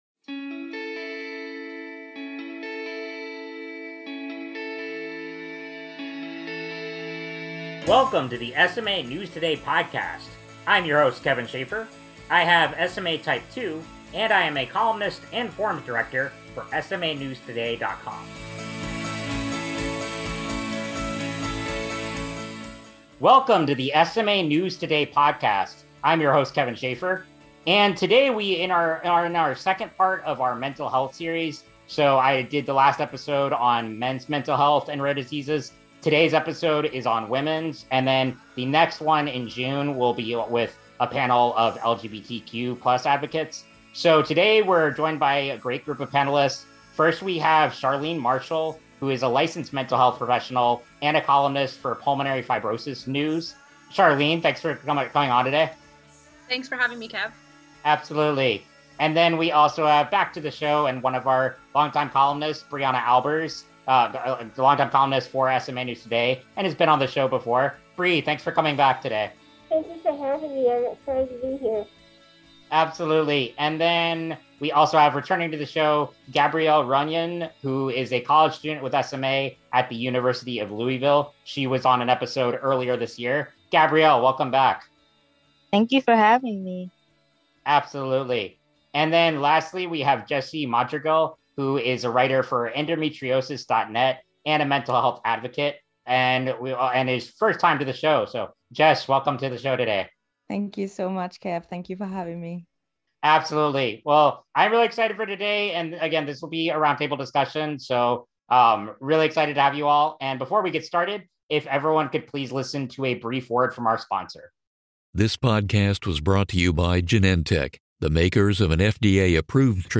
#106: A Conversation About Women’s Mental Health With Rare Disease and Disability Advocates